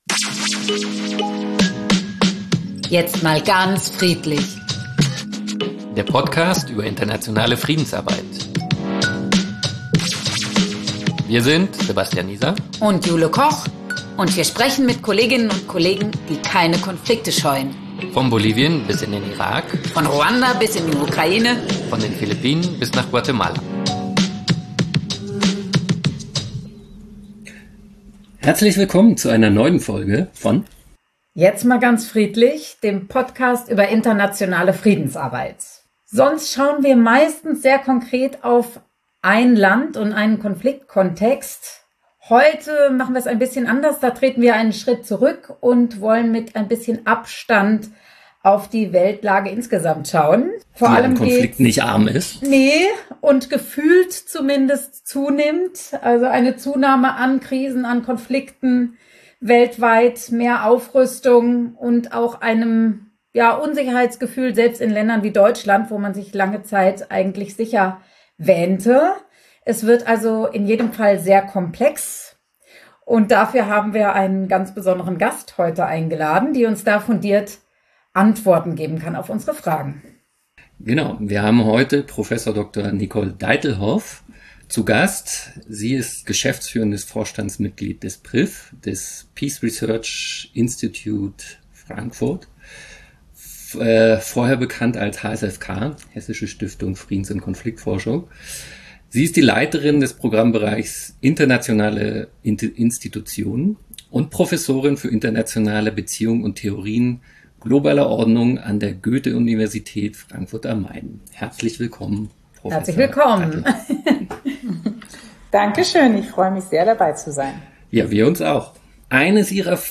sprechen mit Kolleginnen und Kollegen, die das versuchen. In Jetzt mal ganz friedlich geben sie Einblicke in die Lebensrealitäten in Krisenregionen und erzählen Geschichten aus der internationalen Friedensarbeit.